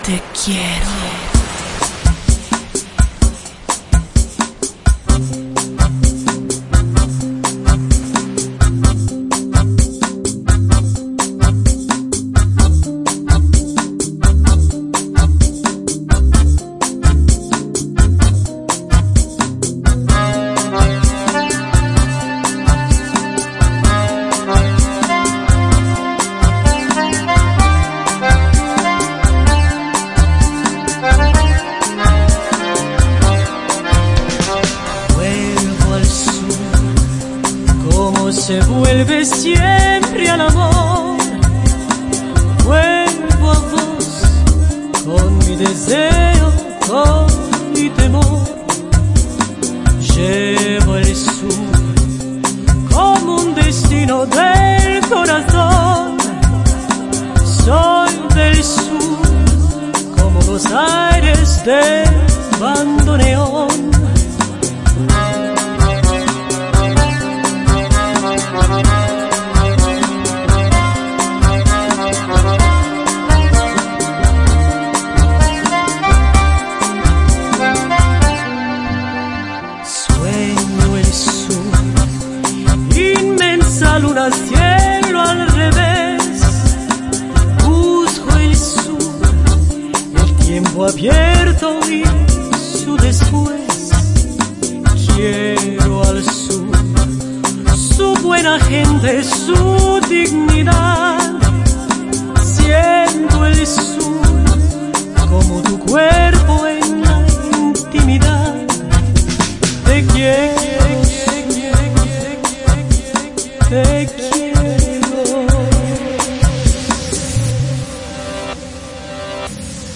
Bandoneon